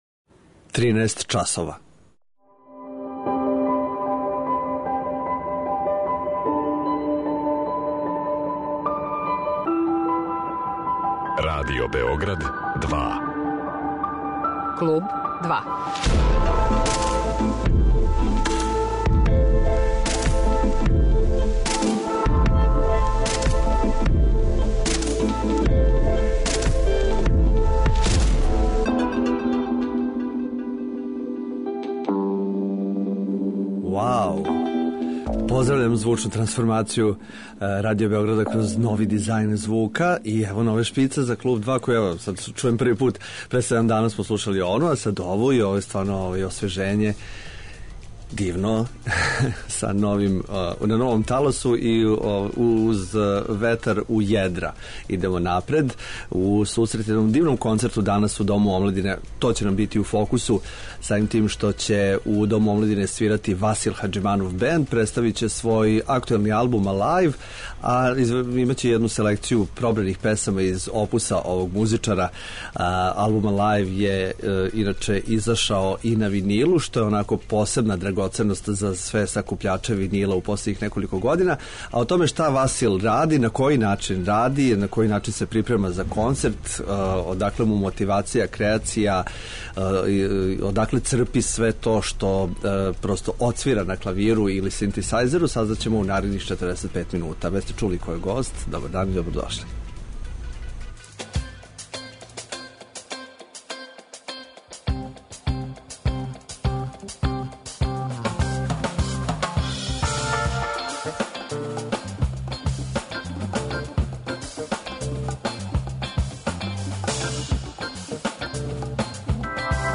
Биће ово узбудљиво џез искуство.